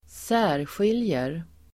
Uttal: [²s'ä:rsjil:jer]